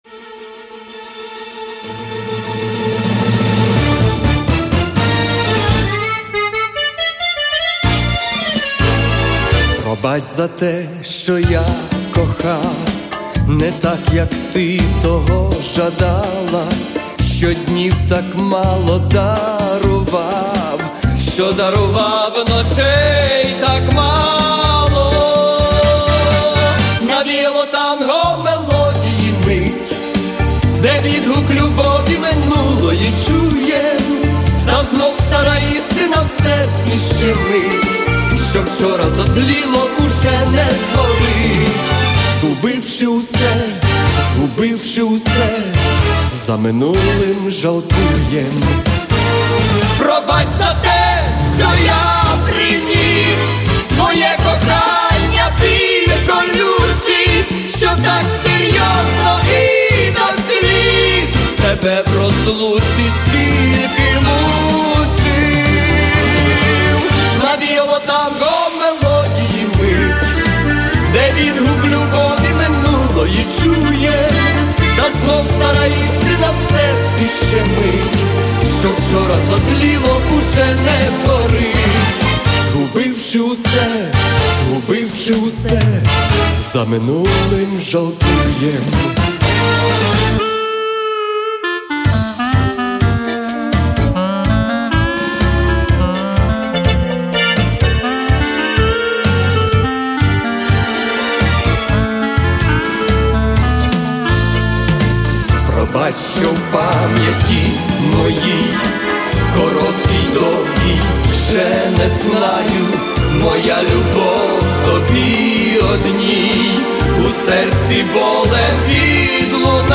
Танго - Пробач.mp3
Рубрика: Поезія, Авторська пісня